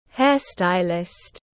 Hairstylist /ˈherstaɪlɪst/